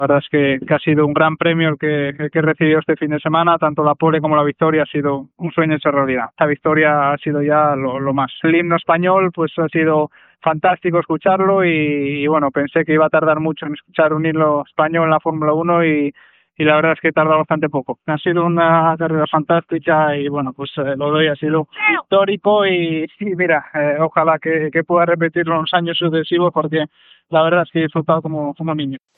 Declaracions del pilot Fernando Alonso després d'haver guanyat el seu primer Gran Premi de Fórmula 1 al circuit d'Hugaroring
Esportiu